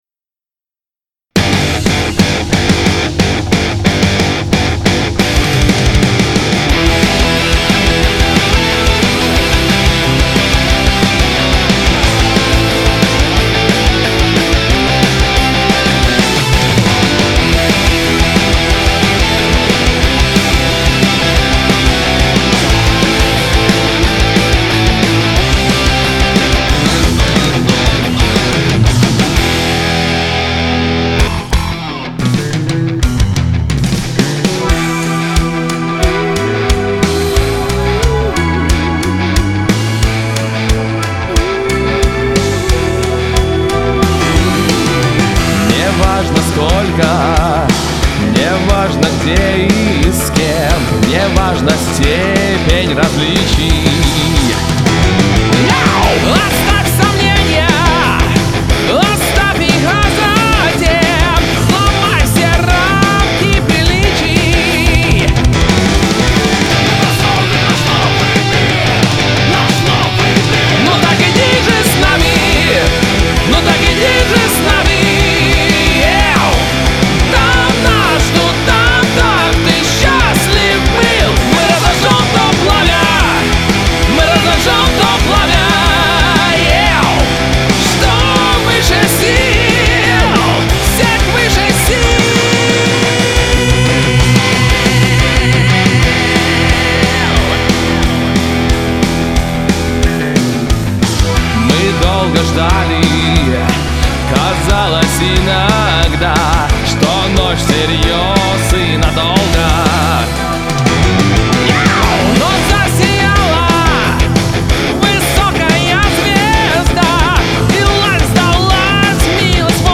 Бодро!